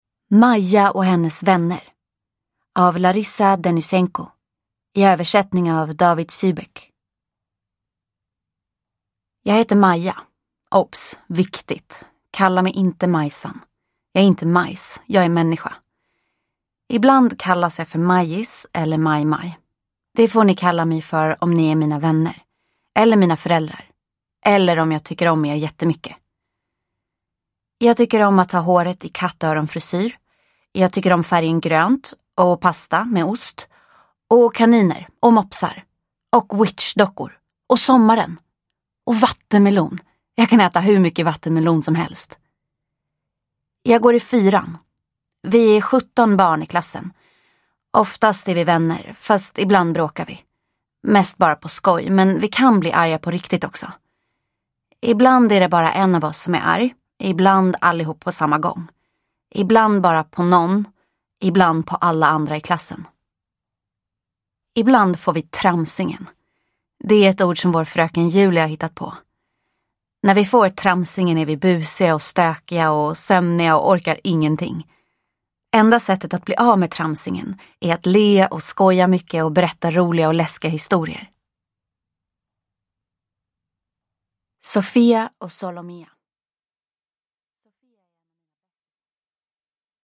Maja och hennes vänner – Ljudbok – Laddas ner